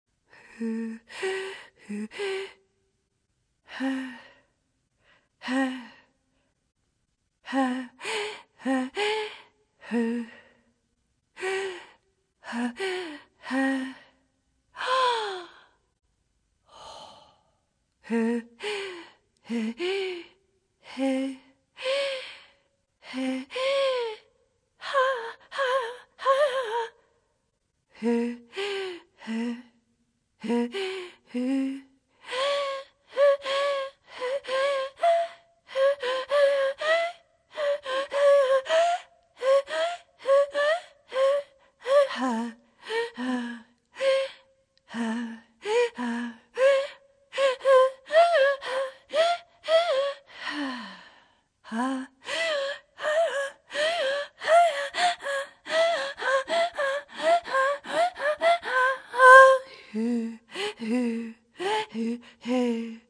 Not just sound, not just song-pure voice.